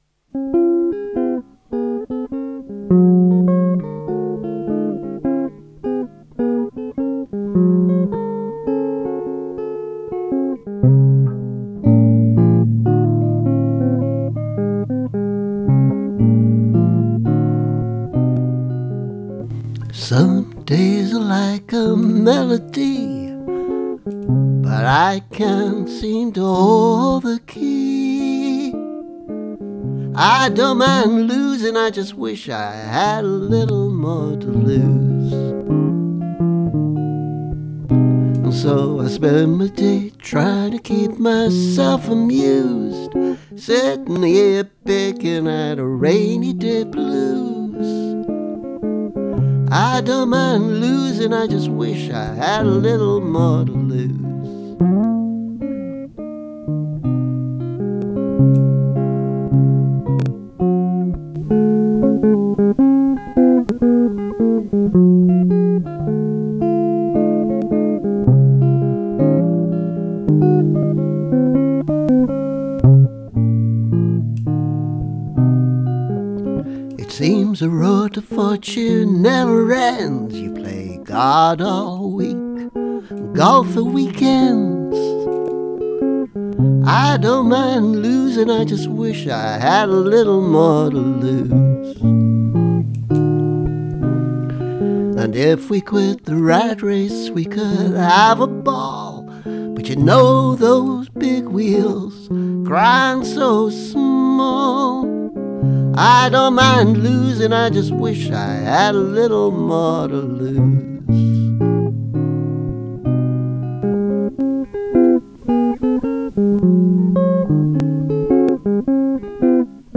I’ve put this up before, but this is a version with electric guitar that I quite like. More jazz than blues, perhaps: I even played it on an archtop guitar.